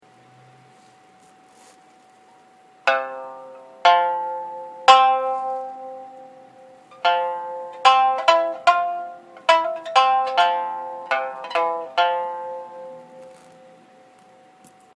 太く長い現在の三線と比べると、繊細かつ穏やかで伸びのある音が特徴といえる。
寝ている心は柔らかでやさしい音になり、大らかさがある。
一般的にはチャーギが柔らかでケヤキは硬い音、楠木はこの中間となる。